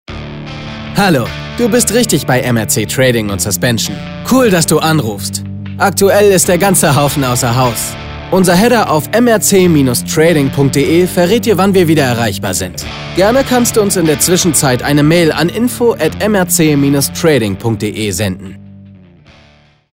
Telefonansage junge Stimme
Seine Stimme klingt jung, cool, frisch und locker.